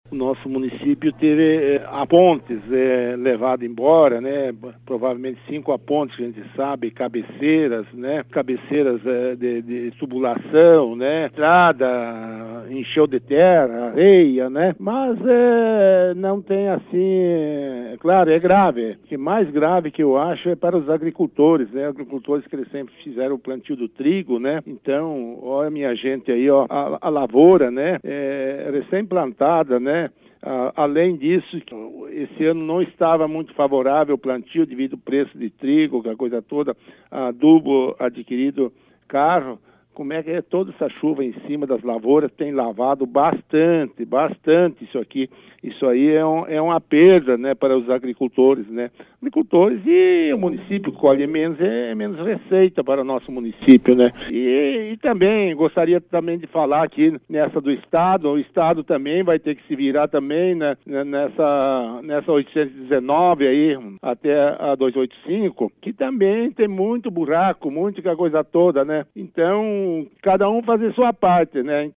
Prefeito de Colorado fala do impacto das fortes chuvas » Grupo Ceres de Comunicação
Em entrevista ao Grupo Ceres de Comunicação, o prefeito do município de Colorado, Celso Gobbi, falou sobre as chuvas que ocorreram na semana passada.